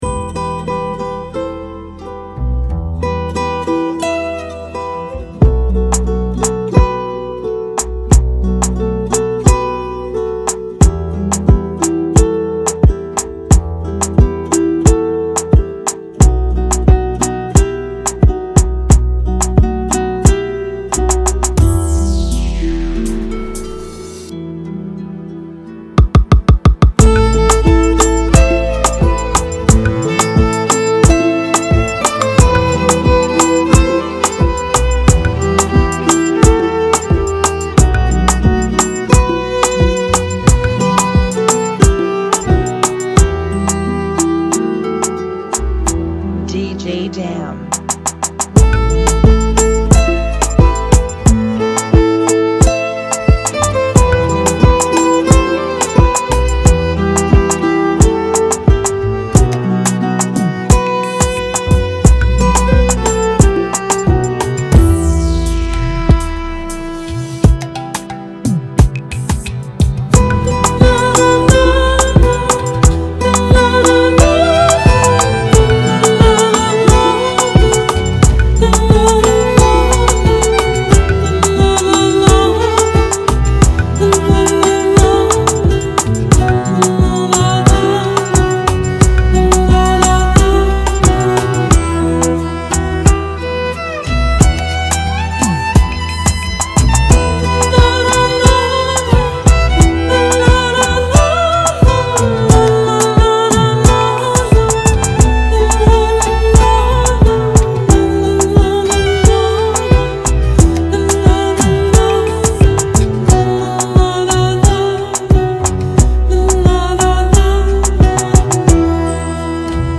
Genre: Kizomba Remix